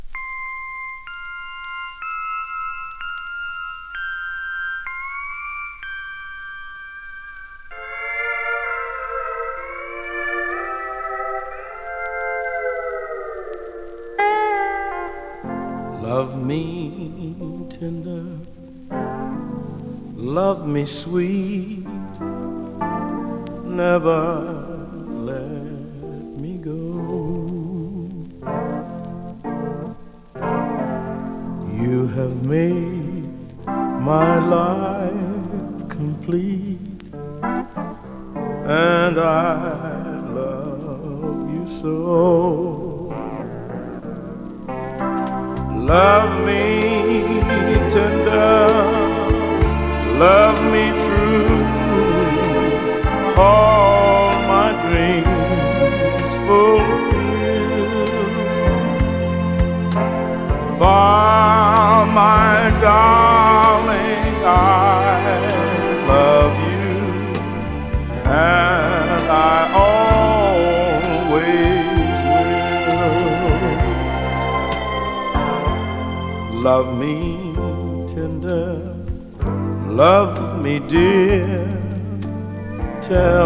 跳ねたビートのハードボイルド・エレクトロニック・ソウルアレンジに仕上がってます。